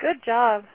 Seven prosodic variants of good job (au files):
enthusiastic
enthusiastic.au